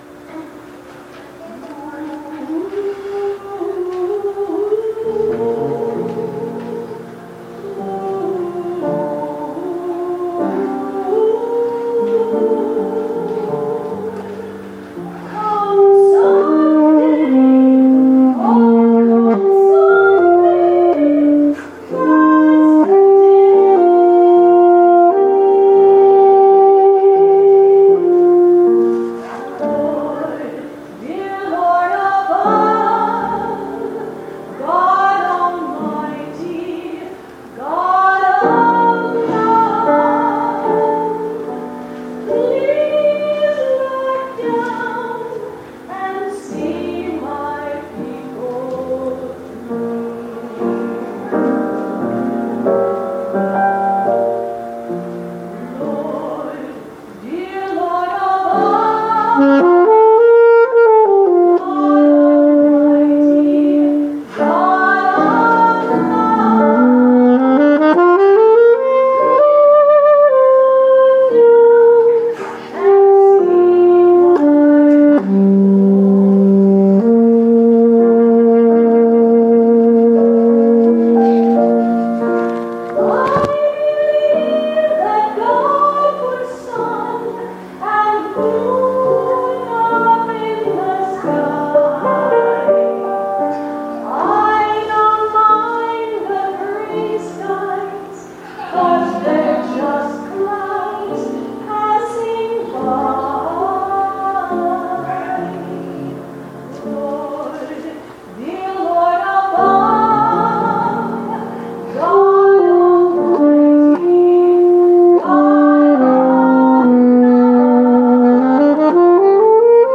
As a bit of a warning, most of the music was performed with very little rehearsal (disclaimer to cover any and all wrong notes, etc.), and all of the recordings were made with the built-in microphone on my iPod (covers balance issues and occasional sags in fidelity).
I added a bit of compression to all of the recordings to help even things out as well. All things considered, most of the songs came out pretty good, but the vocals could all use a little more oomph.
Come Sunday (Spiritual) by Duke Ellington (1899–1974)
saxophone
piano